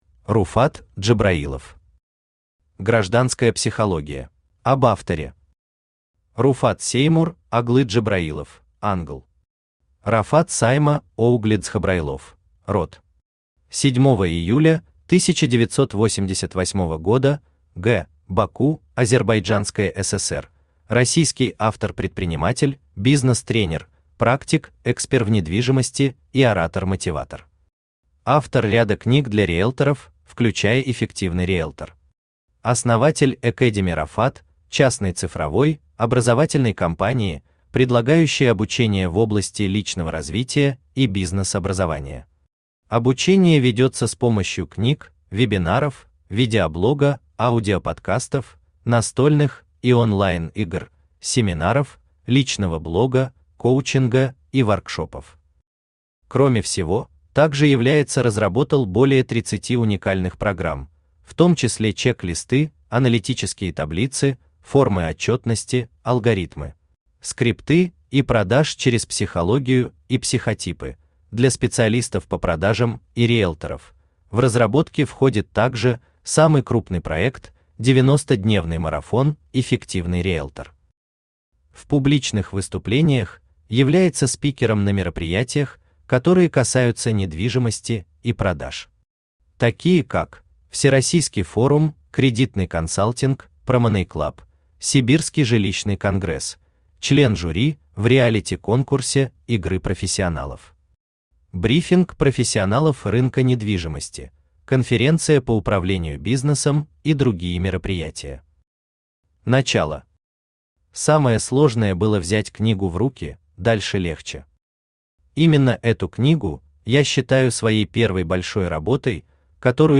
Аудиокнига Гражданская психология | Библиотека аудиокниг
Aудиокнига Гражданская психология Автор Руфат Джабраилов Читает аудиокнигу Авточтец ЛитРес.